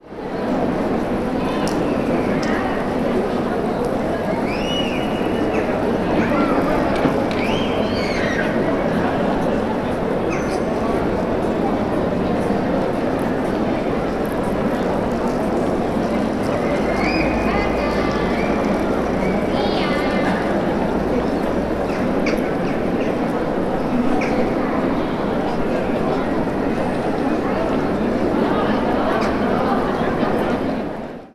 Ambiente tranquilo de zona peatonal
Sonidos: Gente Sonidos: Ciudad